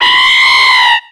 Cri de Delcatty dans Pokémon X et Y.